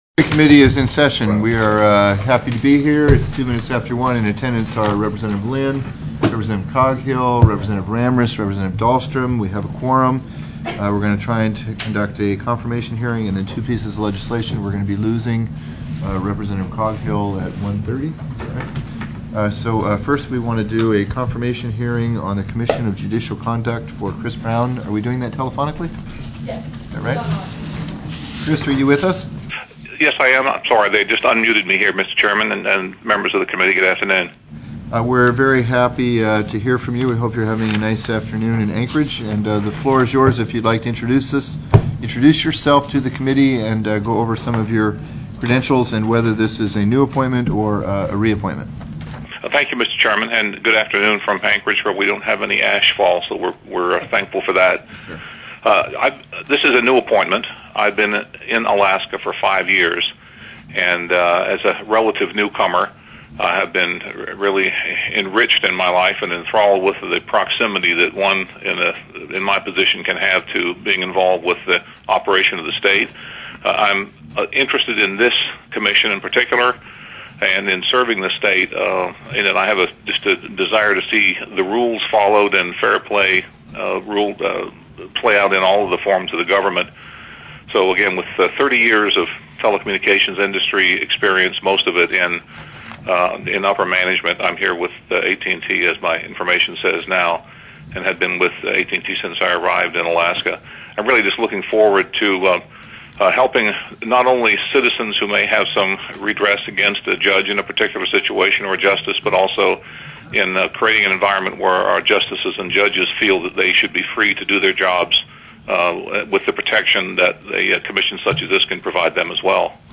03/27/2009 01:00 PM House JUDICIARY
TELECONFERENCED